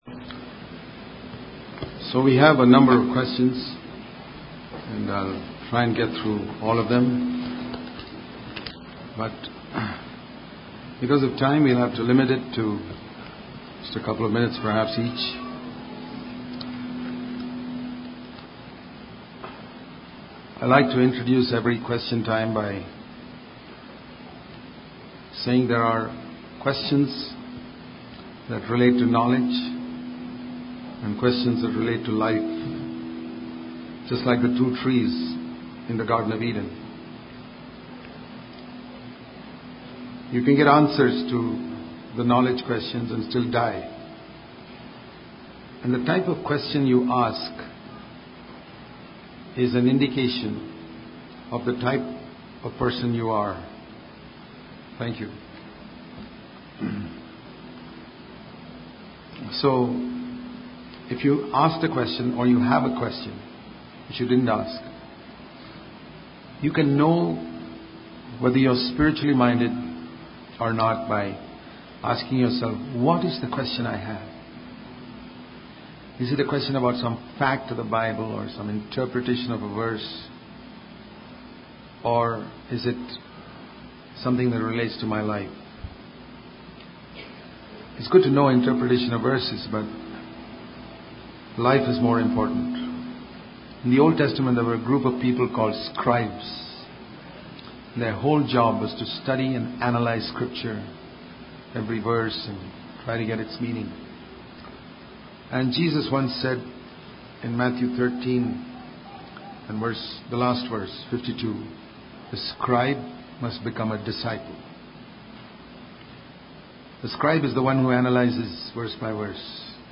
at RLCF, Colorado, USA
8-questions-and-answers-rlcf-family-conference-2015.mp3